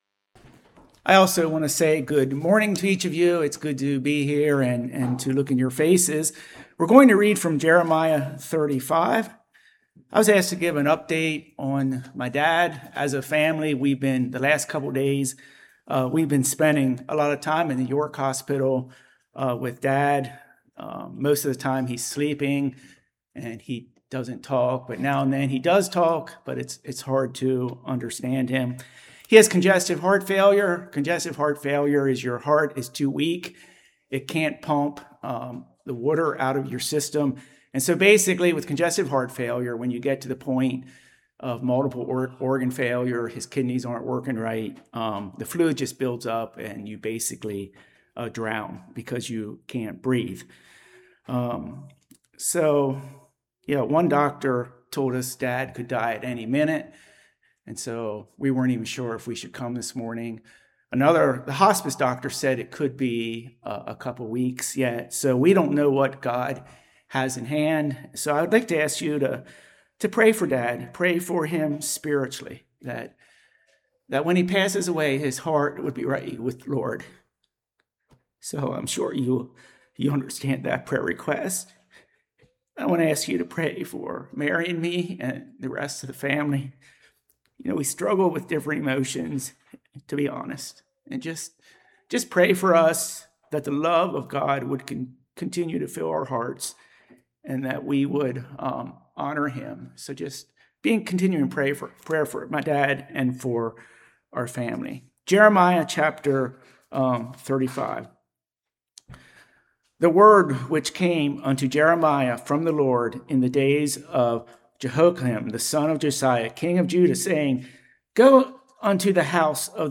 Ephesians 6:1-3 Service Type: Morning Children’s’ Relationship with Parents.